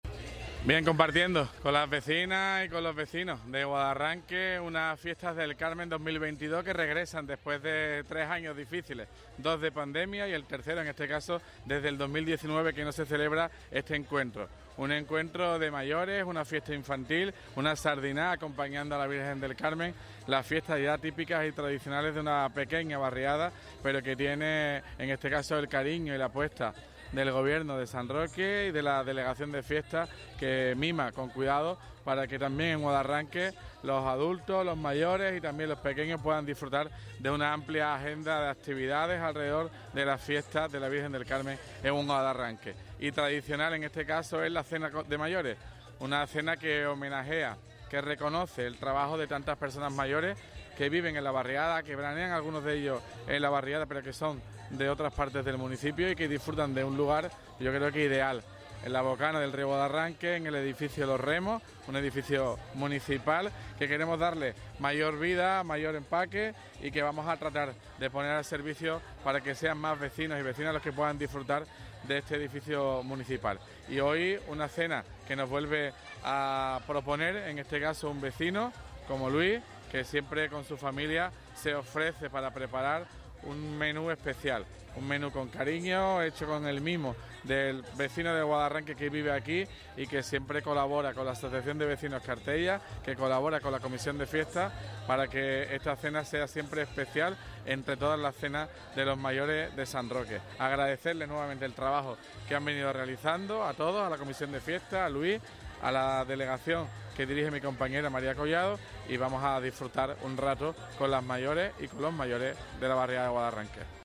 TOTAL_ALCADE_cena_guadarranque.mp3